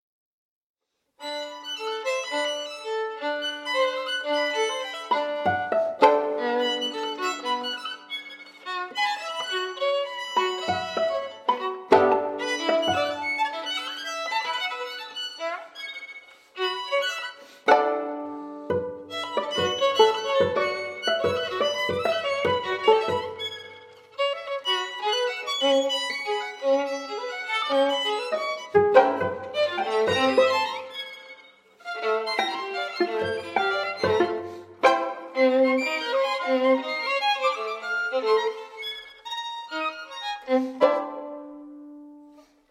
string quartet
violin
viola
cello